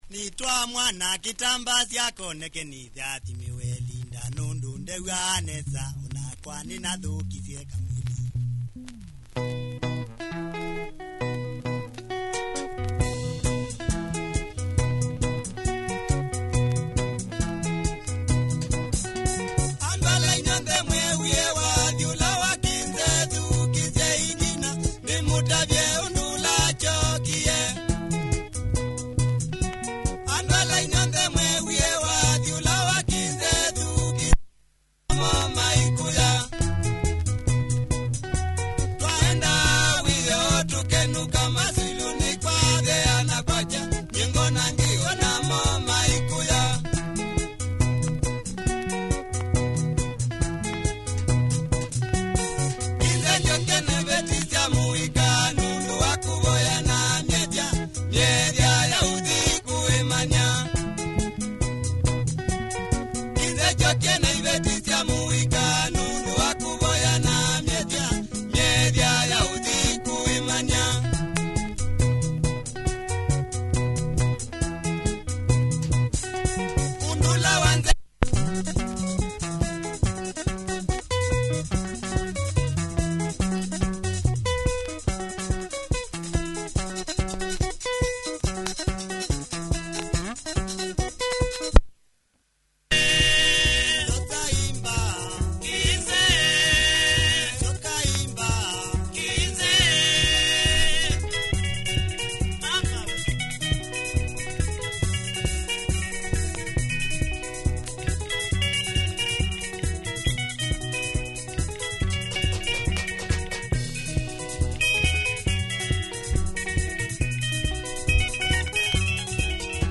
Quality Kamba benga mover